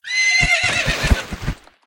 PixelPerfectionCE/assets/minecraft/sounds/mob/horse/death.ogg at mc116